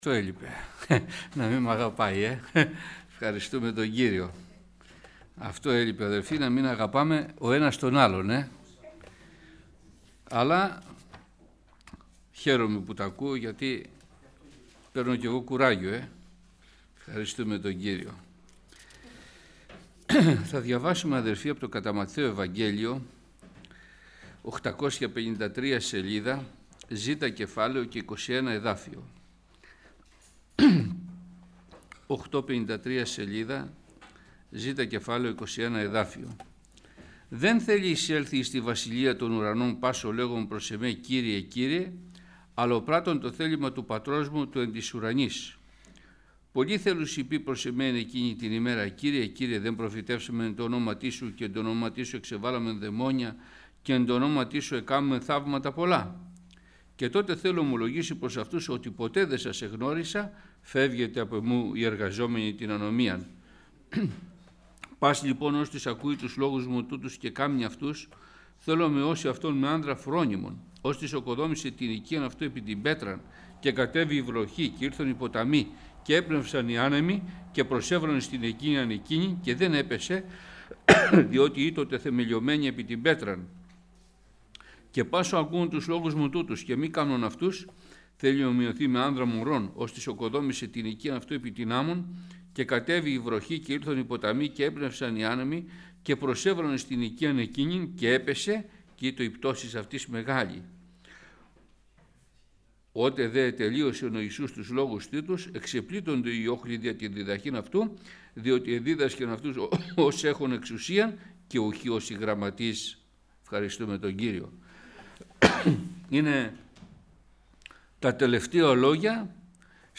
Κηρυγμα Ευαγγελιου – Ε.Α.Ε.Π Πετραλώνων
Κηρυγμα Ευαγγελιου